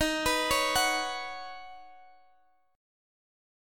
Listen to D#m7#5 strummed